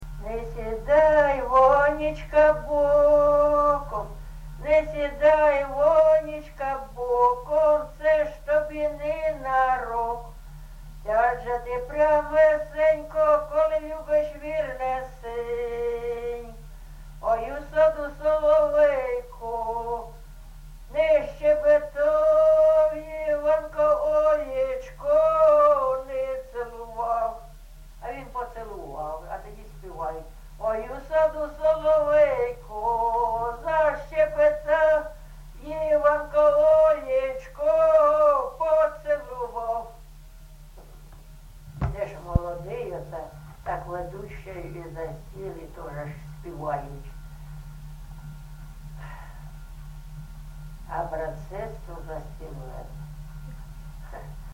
ЖанрВесільні
Місце записус. Софіївка, Краматорський район, Донецька обл., Україна, Слобожанщина